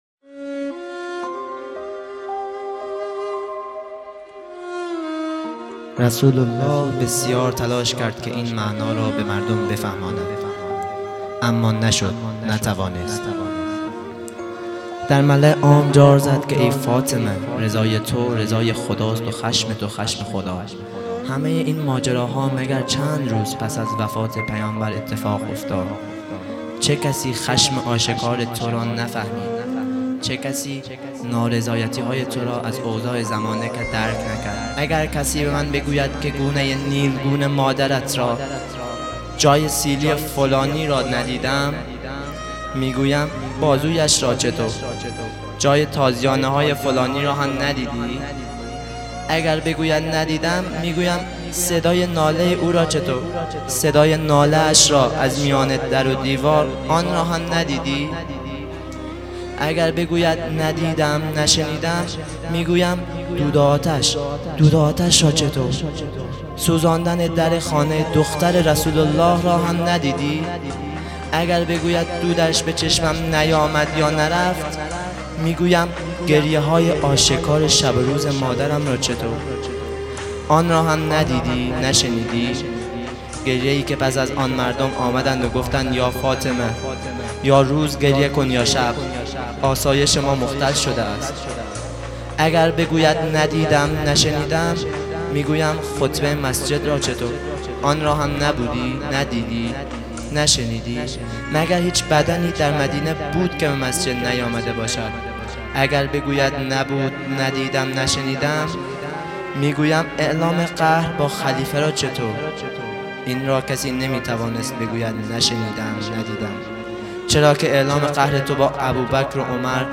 فاطمیه 1443 | شب دوم | 16 دی 1400